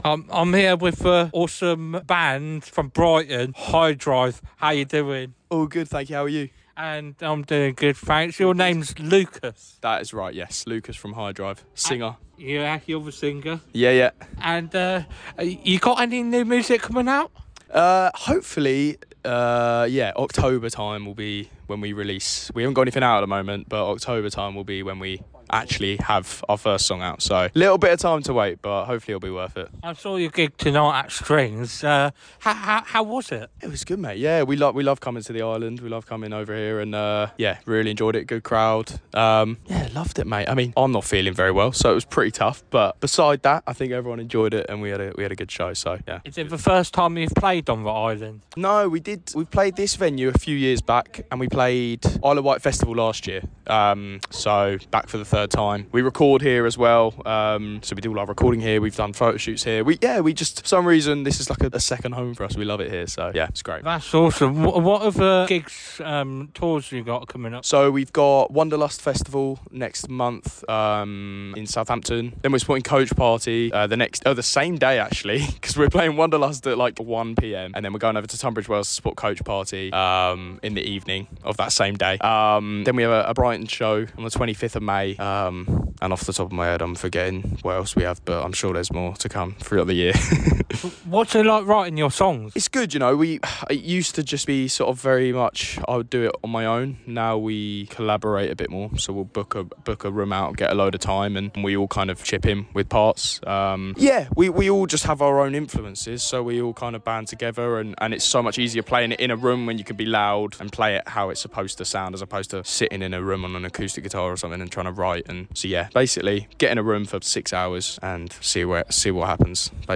Highdrive Strings interview 2025